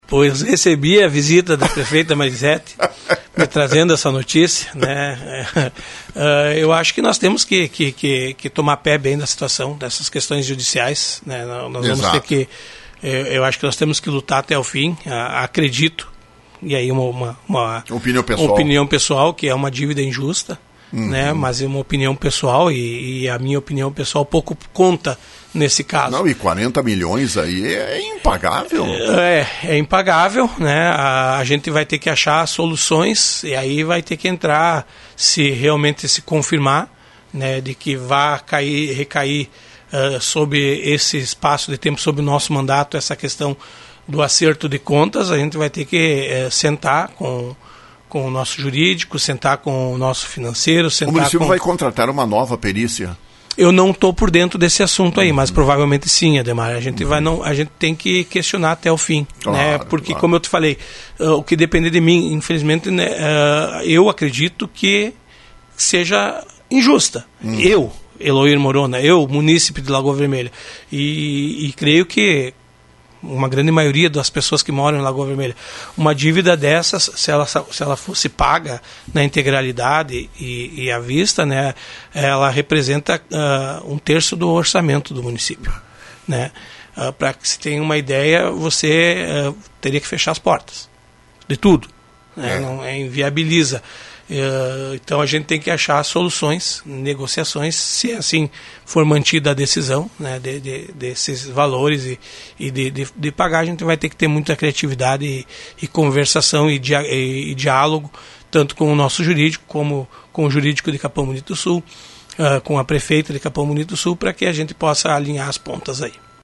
Futuro prefeito de Lagoa Vermelha, Eloir Morona, foi questionado, pela Rádio Lagoa FM, na manhã desta quinta-feira, sobre a dívida de Lagoa Vermelha para com Capão Bonito do Sul na ordem de 40 milhões de reais.